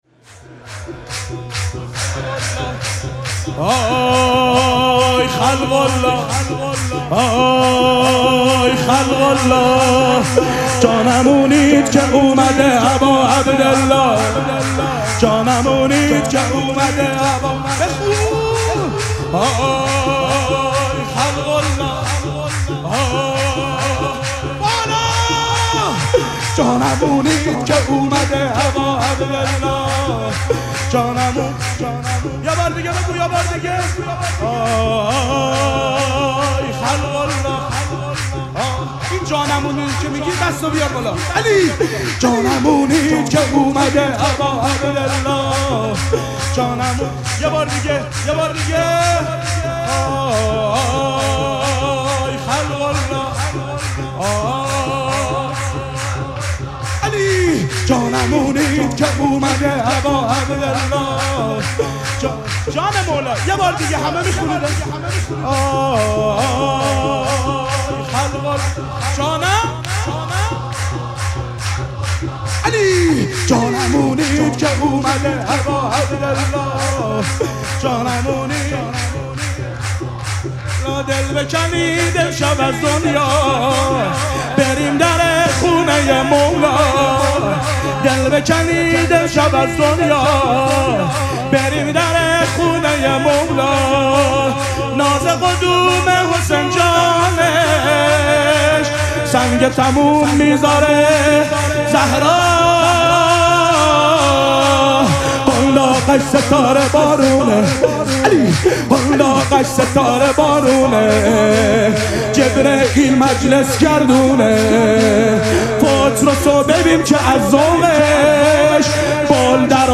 شب اول مراسم جشن ولادت سرداران کربلا
شور
مداح